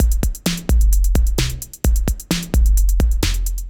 Index of /musicradar/80s-heat-samples/130bpm